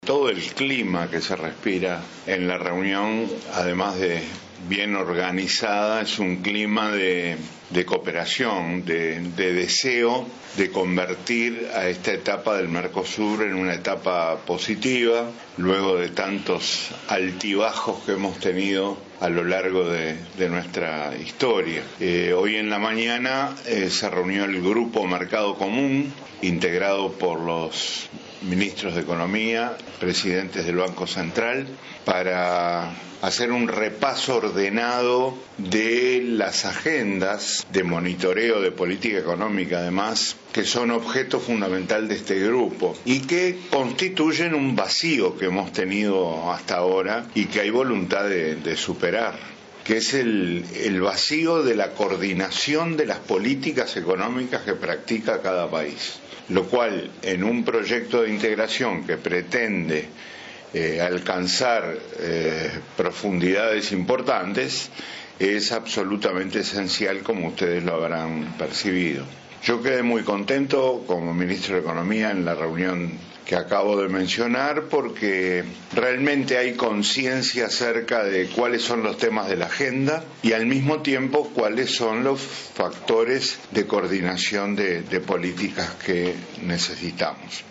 “Todo el clima que se respira en la reunión es de cooperación, de deseo de convertir esta etapa del Mercosur en positiva luego de tantos altibajos”, afirmó el ministro de Economía, Danilo Astori, en un alto de la reunión del Consejo del Mercado Común en Mendoza, Argentina. Agregó que se busca la integración de las políticas de los estados miembros.